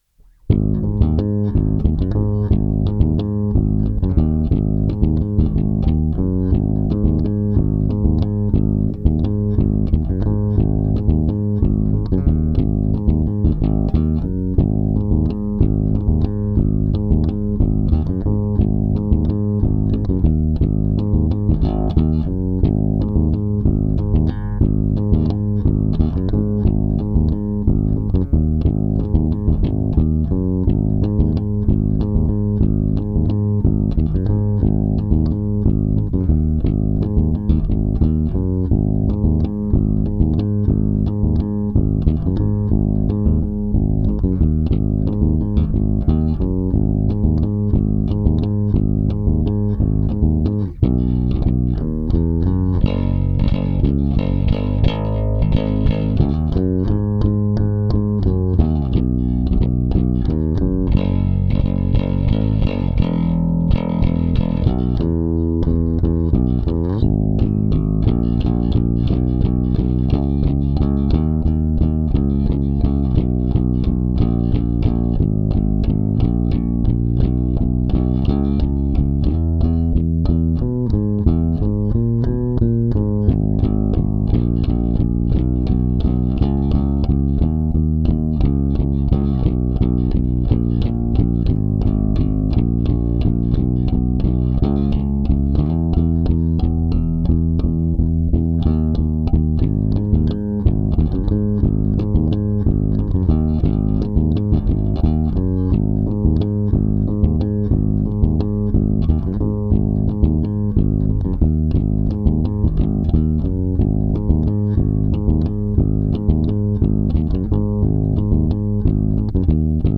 (nur bass)